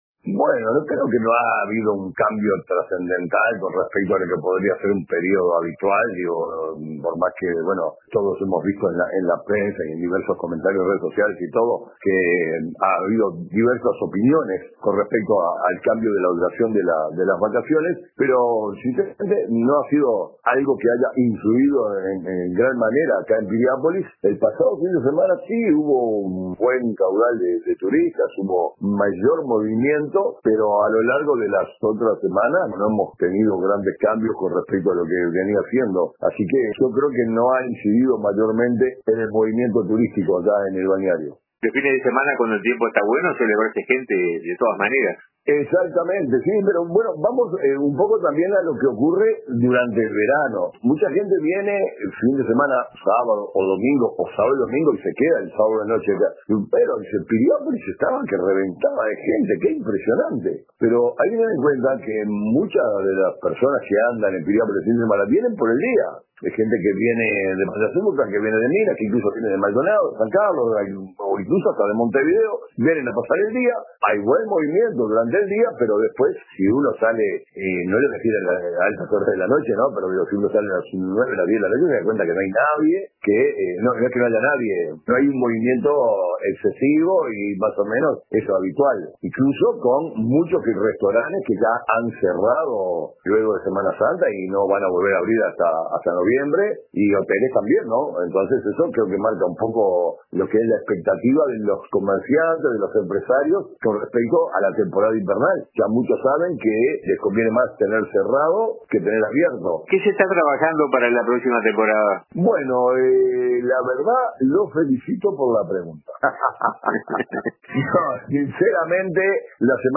En una entrevista con RADIO RBC